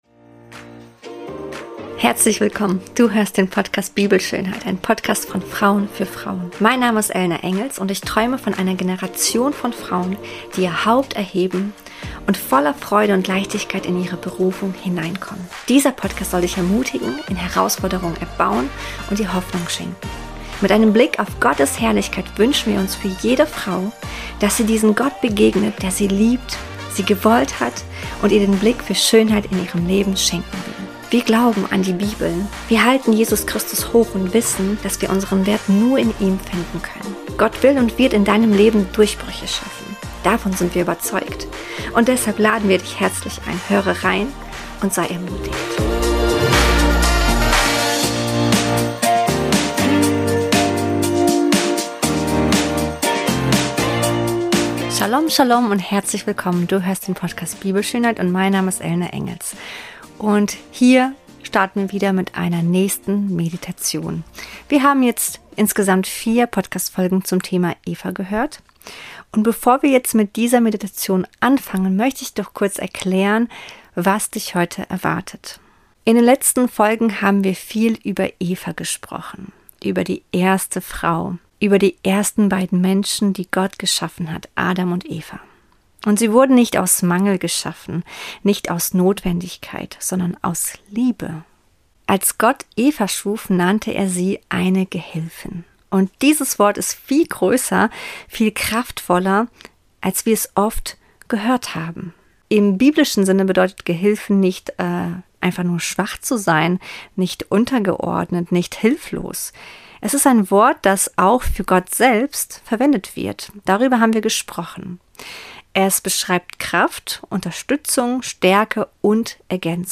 Diese Meditation ist eine biblische Meditation.